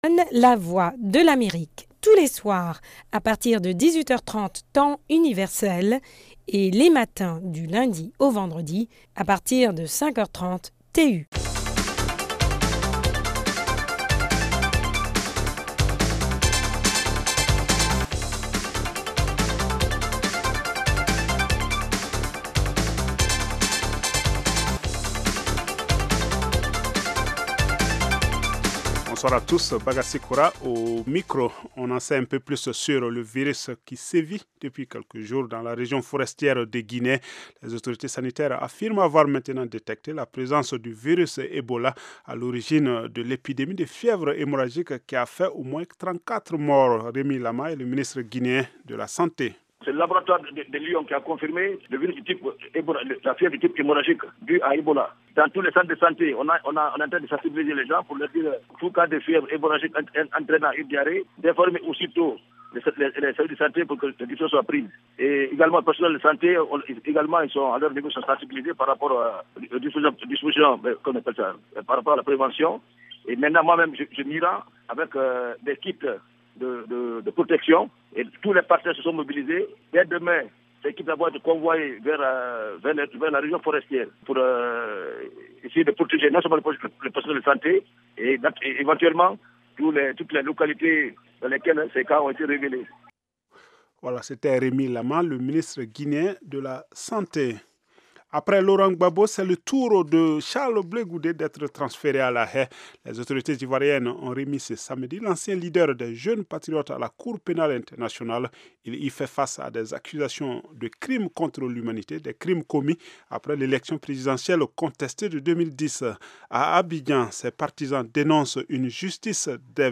Egalement un entretien avec Ndioro Ndiaye, ancienne Ministre du genre sénégalaise et coordonnatrice du Réseau Francophone de l'Egalité femme-homme.